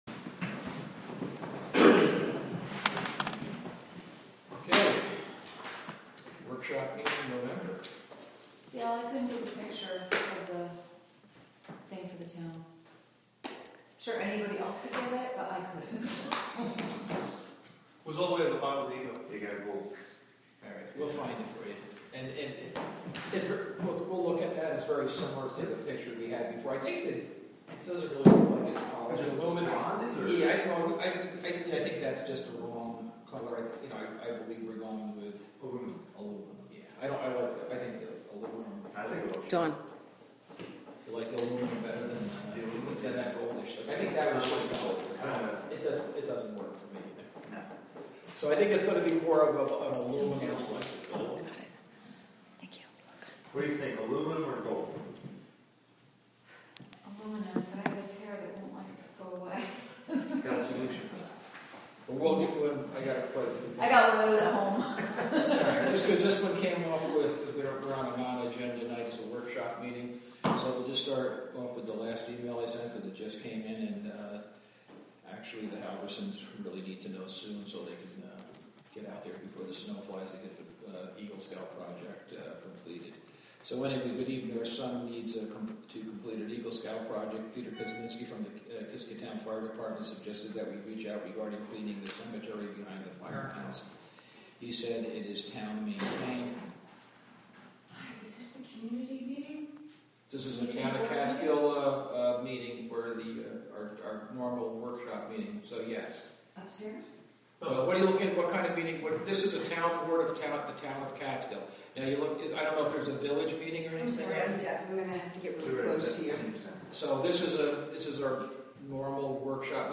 The Town of Catskill holds their monthly meeting.
WGXC is partnering with the Town of Catskill to present live audio streams of public meetings.